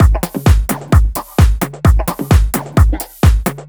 Mono Phunk 01.wav